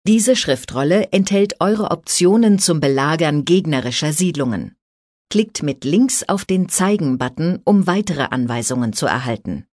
Kampagnenberaterin: